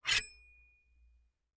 Metal_36.wav